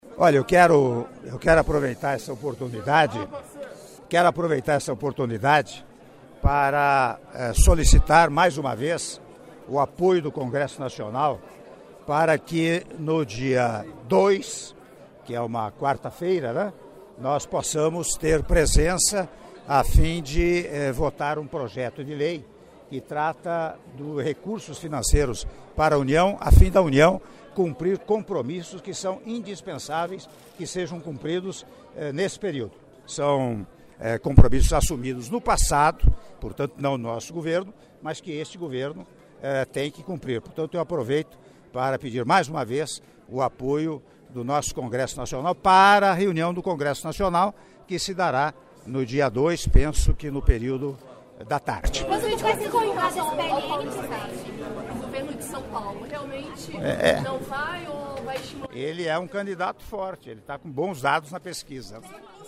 Áudio da entrevista concedida pelo Presidente da República, Michel Temer, após cerimônia de entrega do Selo Nacional de Responsabilidade Social pelo Trabalho no Sistema Prisional (Selo Resgata) - Brasilia-DF - (01min02s)